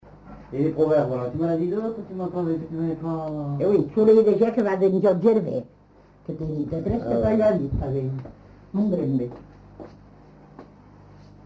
Aire culturelle : Comminges
Effectif : 1
Type de voix : voix de femme
Production du son : récité
Classification : proverbe-dicton